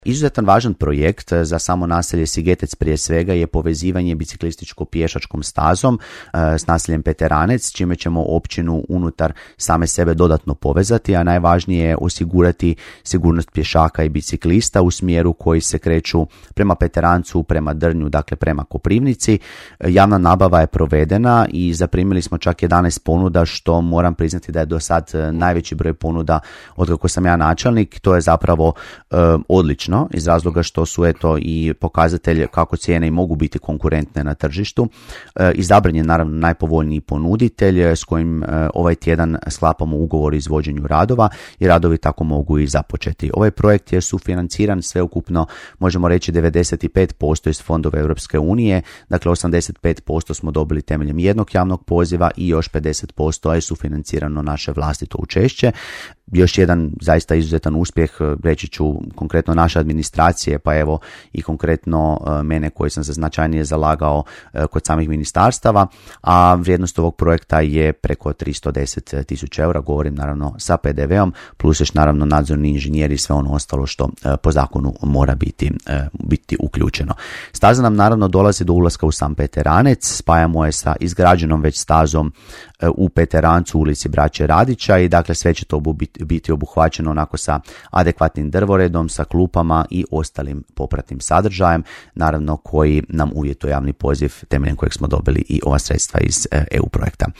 Načelnik općine Peteranec Ivan Derdić gostovao je u programu Podravskog radija.